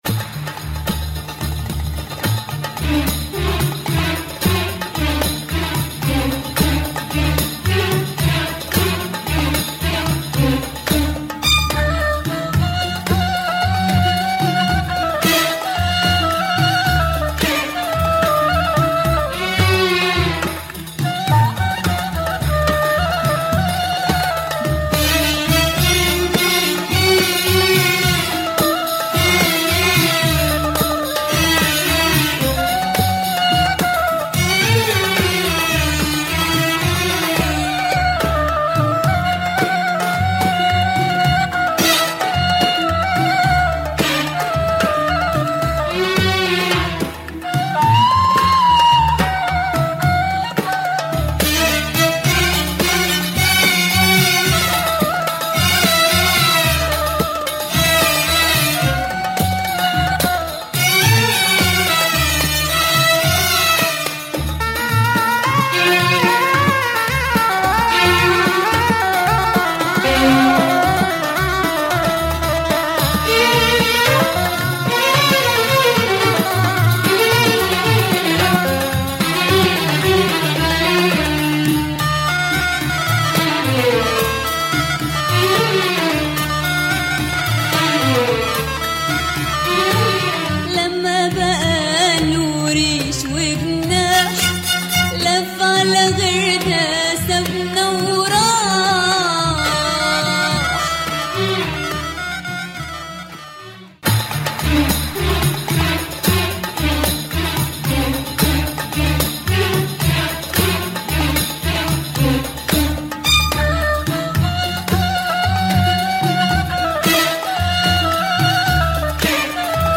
Moroccan female singer
delivers some super cool Arabic beats.